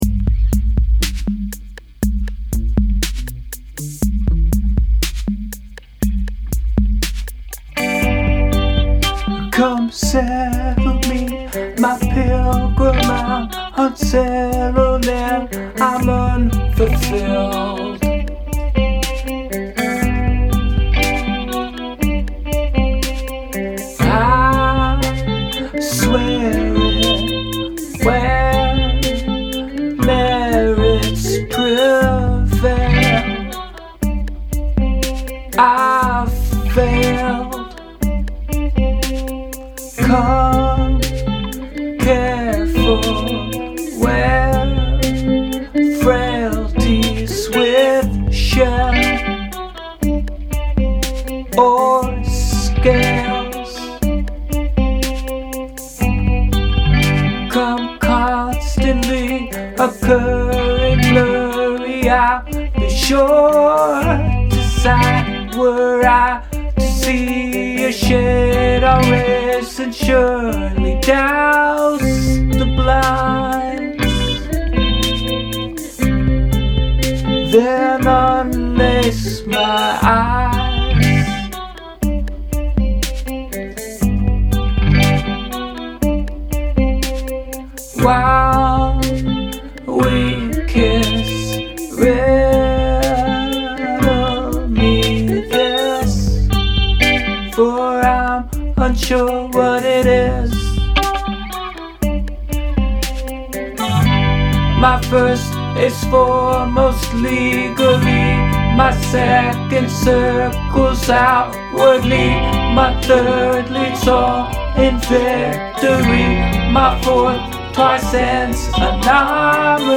verse: G(riff), Am, Bm, C, G(riff), Am, Bm, C, G(riff)
bridge: G, C, Bflat minor, A major, G
figured tonight was the right time to knock it out. i picked a beat that sounded ok, and slowed it down to half tempo, then recorded a couple tracks of the main riff followed by some punctuated guitar chords. for the words i did some free writing before settling on the first line "come settle me my pilgrim". i was scanning my bookshelf and saw the book pilgrim at tinker creek, one of my favorites. the chords on the bridge section are pretty random i think. they are G major, C major, Bflat minor, A major. the words there are a riddle i found on the internet